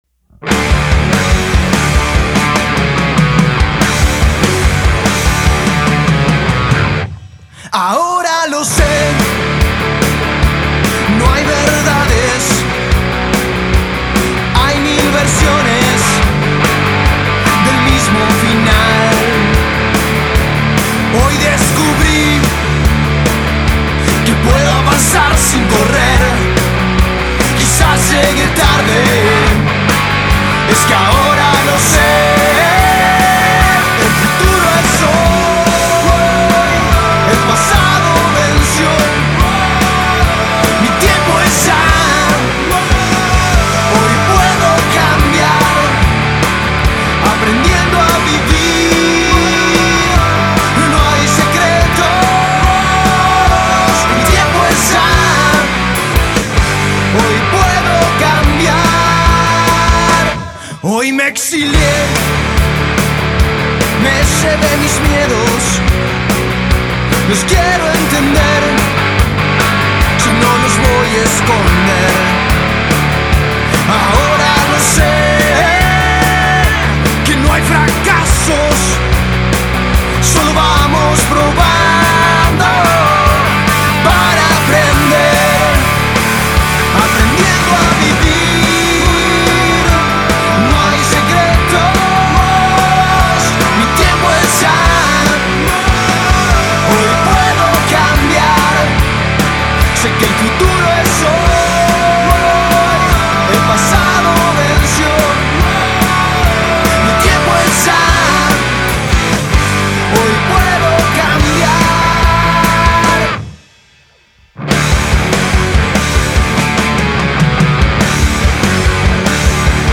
Rock Alternativo